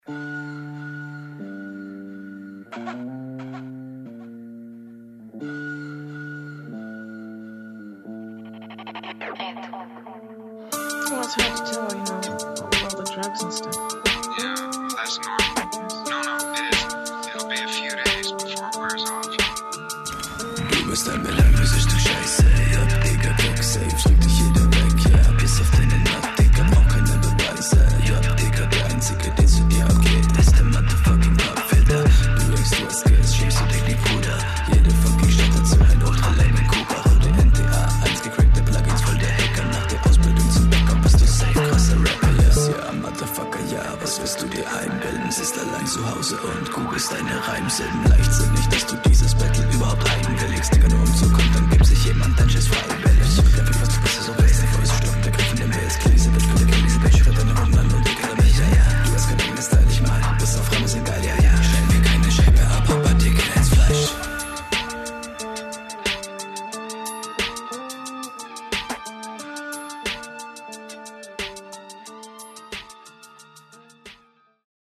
komische stimmlage. hättest bisschen verständlicher abmischen können.
das klingt alles voll cool so aber ich verstehe wirklich kein wort